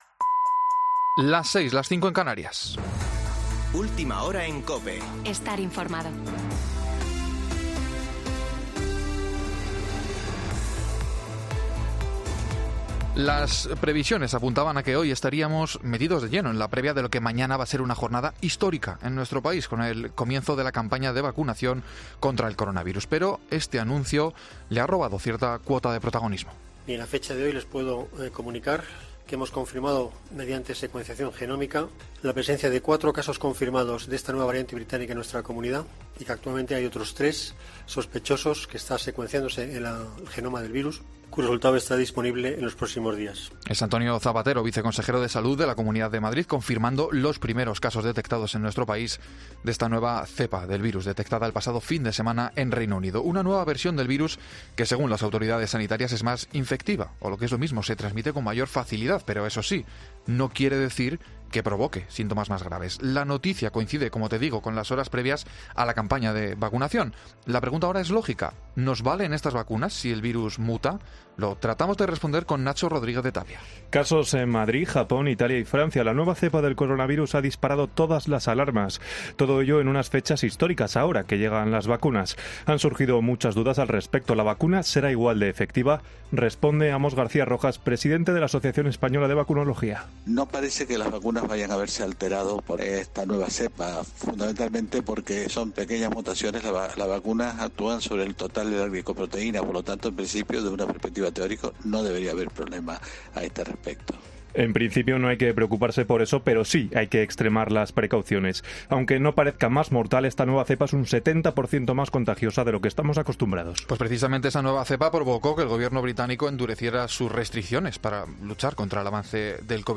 AUDIO: Boletín de noticias de COPE del 26 de diciembre de 2020 a las 18.00 horas